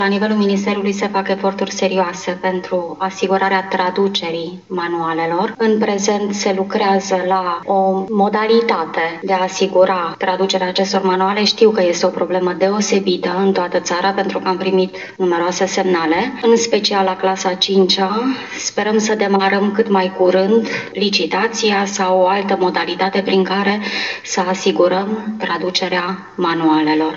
Secretarul de stat pentru minorități din cadrul Ministerului Educației, Irina Elisabeta Kovacs, a arătat că ministerul caută soluții pentru rezolvarea cât mai rapidă a crizei manualelor.